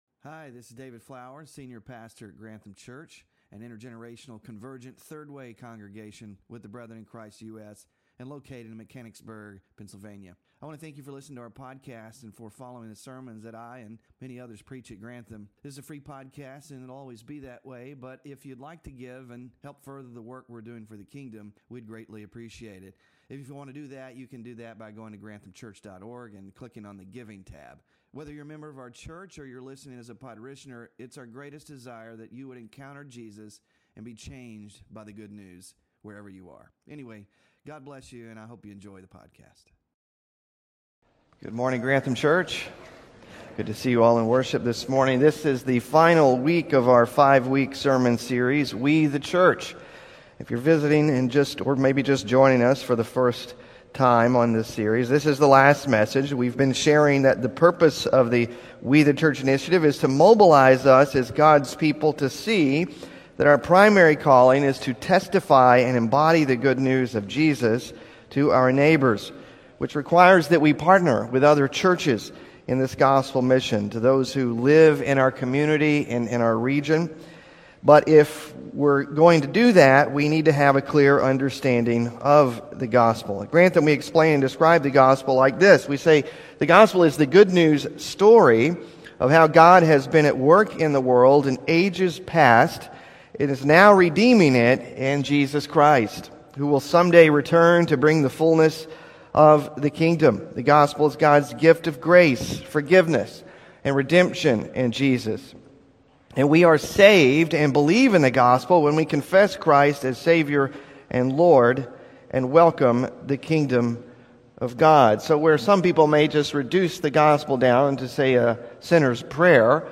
So, what does it look like to share the gospel with others? And how do we make disciples who make disciples? In the final message of our series